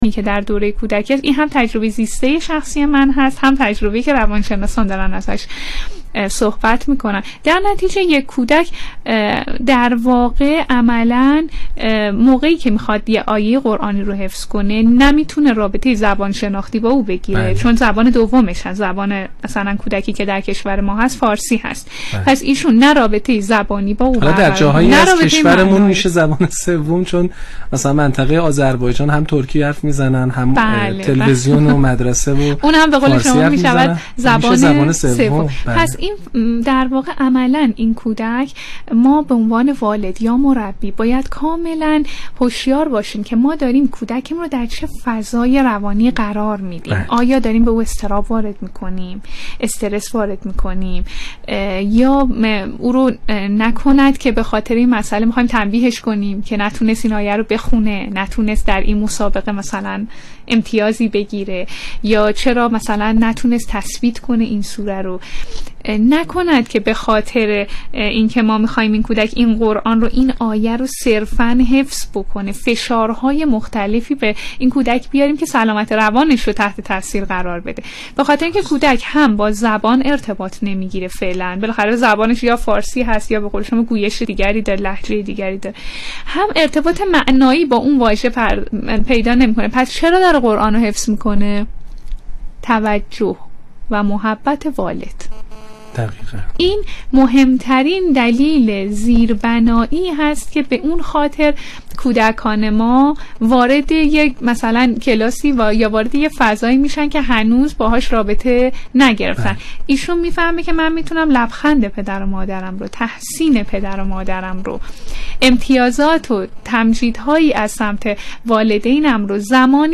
میهمان تلفنی این برنامه مصطفی رحماندوست، نویسنده و شاعر بود.